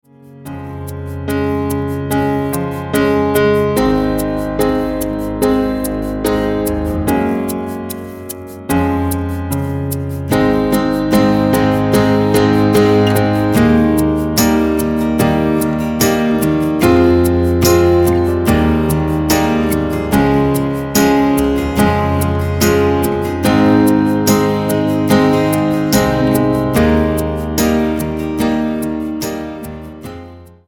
Tonart:Ab ohne Chor